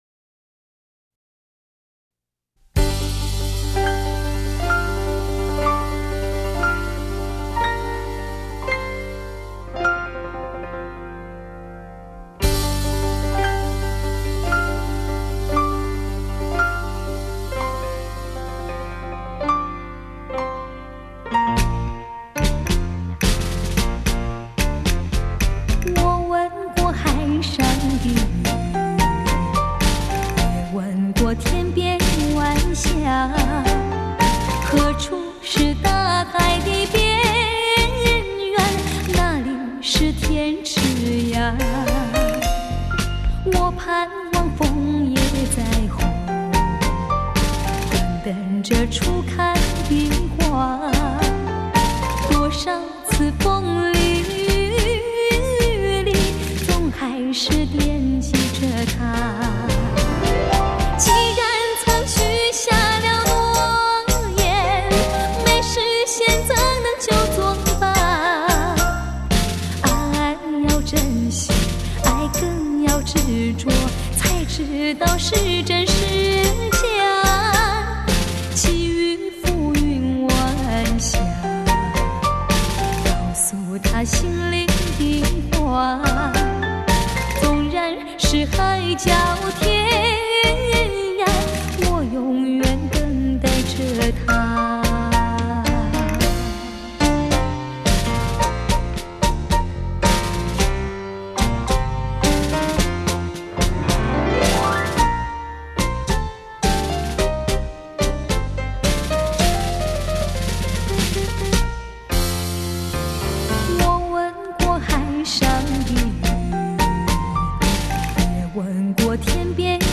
柔美醉人的歌声迎千禧 再次激荡起汹涌的情怀